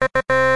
点击4.mp3